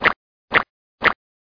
squelch.mp3